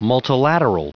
Prononciation du mot multilateral en anglais (fichier audio)
Prononciation du mot : multilateral